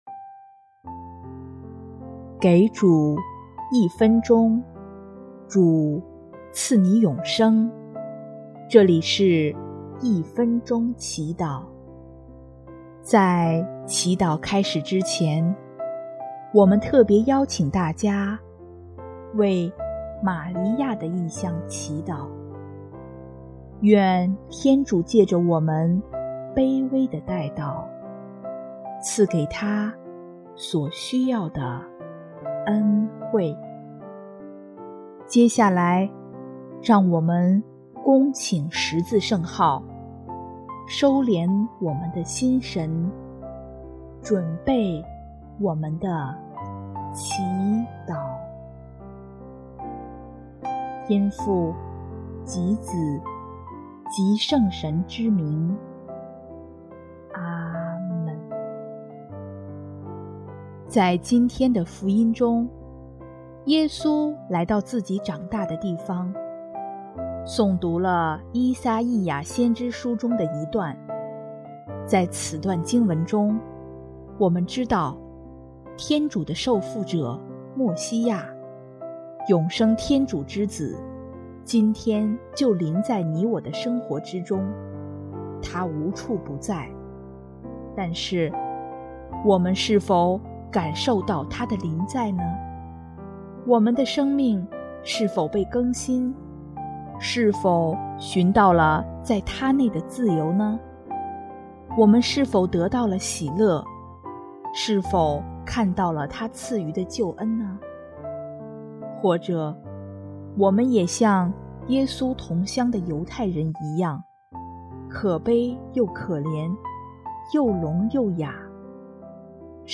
音乐：第三届华语圣歌大赛参赛歌曲《听主》